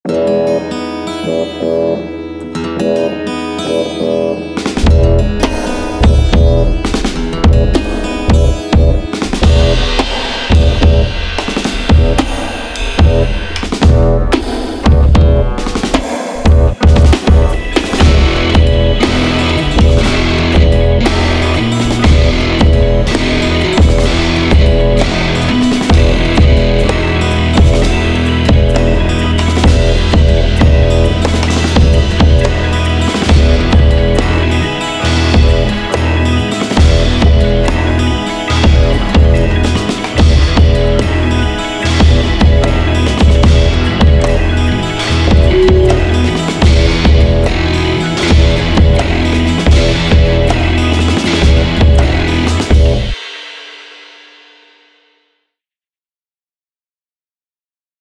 The drums sound fake.
2nd A-section
Sure, it’s just a drone on E, but who cares, it grooves. And that trashy china-esq crash cymbal that keeps coming in and out (at double duration each time — eighth notes, then quarter, half, whole, breve) sits perfectly in the mix.